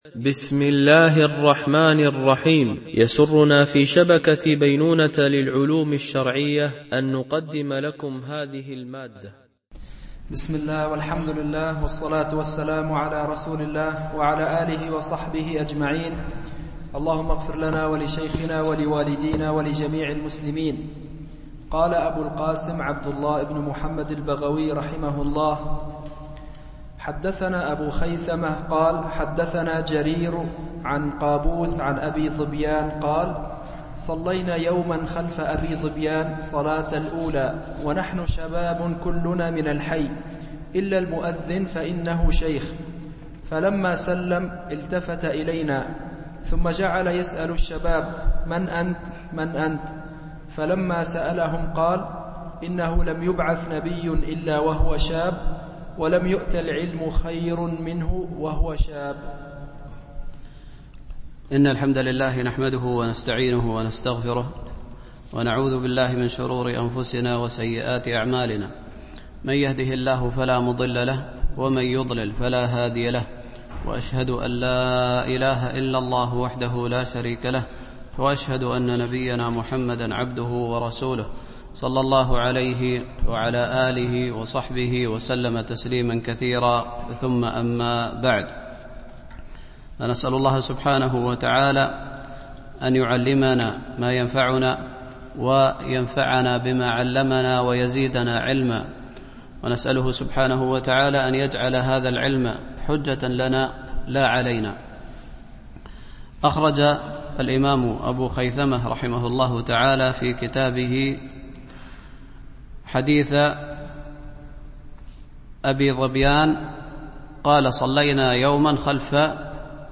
شرح كتاب العلم لأبي خيثمة ـ الدرس 26 (الأثر 80-81)